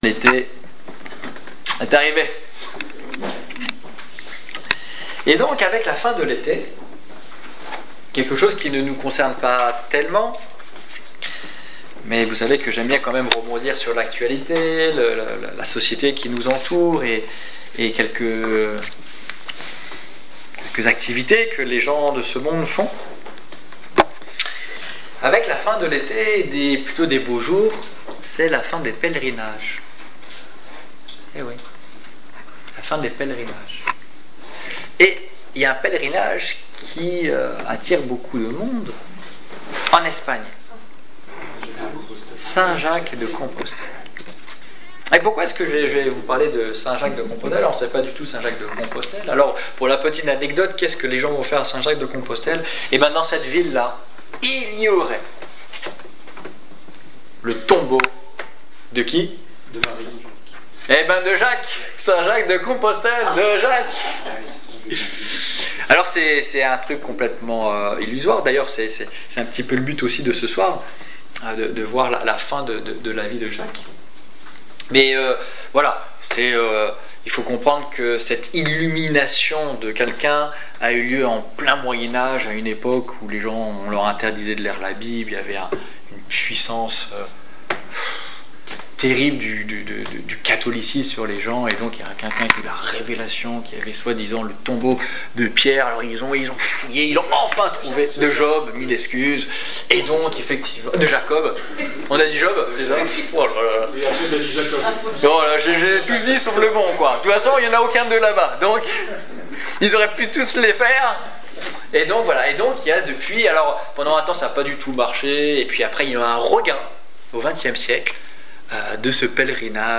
Annonce de l'évangile du 16 septembre 2016